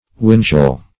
Search Result for " windgall" : The Collaborative International Dictionary of English v.0.48: Windgall \Wind"gall`\, n. (Far.)